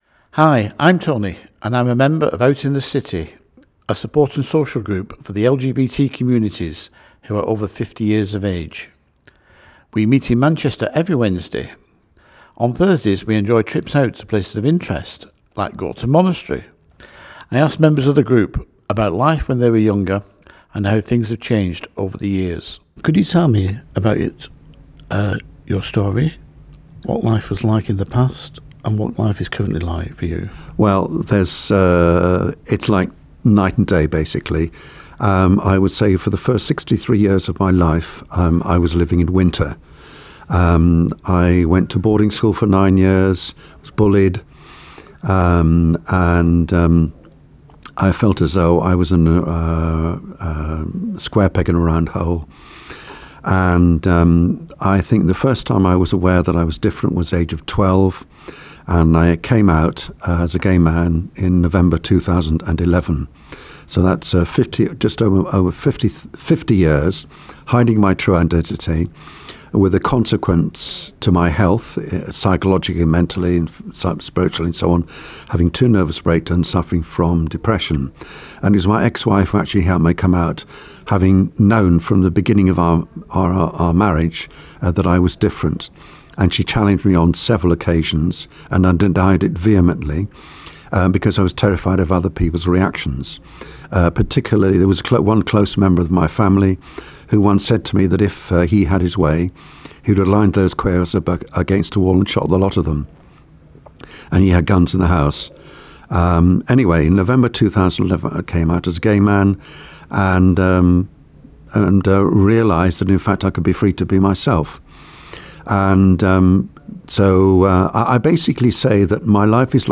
This is a podcast for Sonder Radio featuring interviews with members of Out In The City.
01-oitc-interviews.mp3